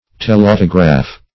Telautograph \Tel*au"to*graph\, n. [Gr. th^le far + autograph.]